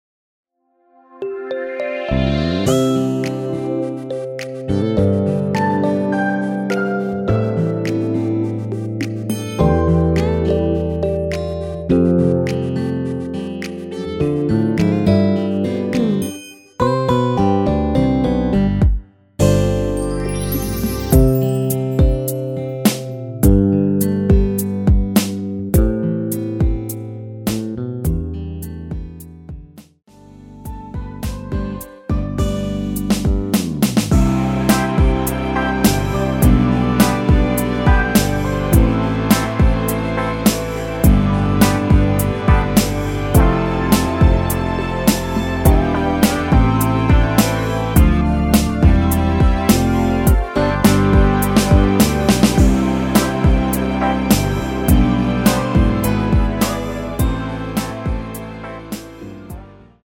앨범 | O.S.T
노래방에서 음정올림 내림 누른 숫자와 같습니다.
음정은 반음정씩 변하게 되며 노래방도 마찬가지로 반음정씩 변하게 됩니다.
앞부분30초, 뒷부분30초씩 편집해서 올려 드리고 있습니다.
중간에 음이 끈어지고 다시 나오는 이유는
위처럼 미리듣기를 만들어서 그렇습니다.